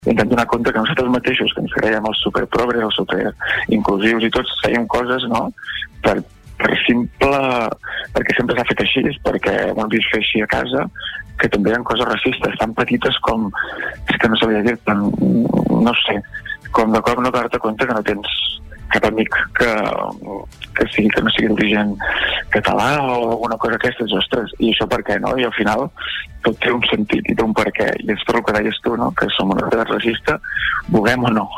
PalafrugellEntrevistes Supermatí